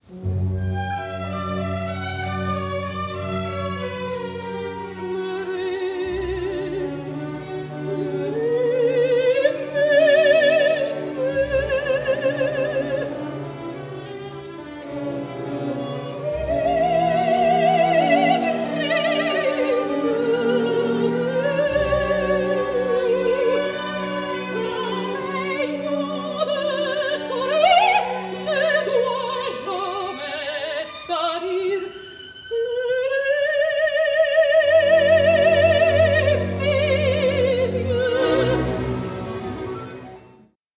86.06 min. (mono)
• Remastered from the original 16mm Kinescope soundtrack
• Best sound available